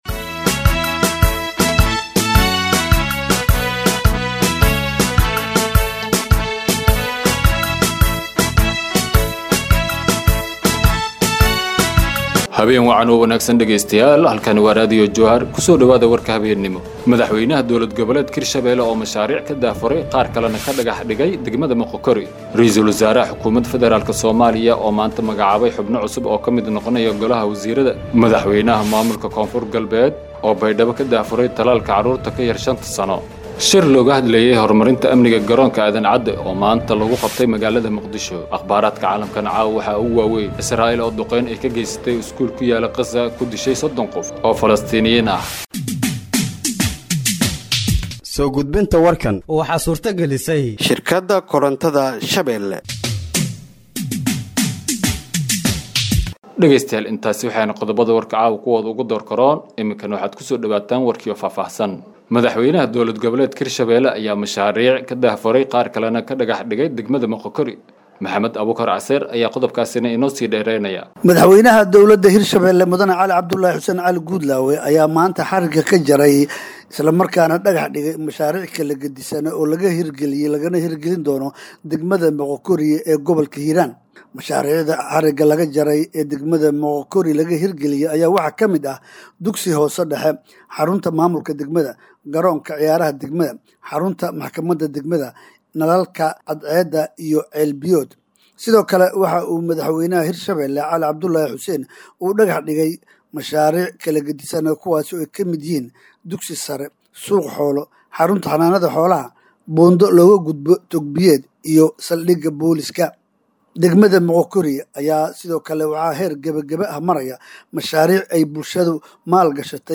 Warka habeenimo ee radiojowhar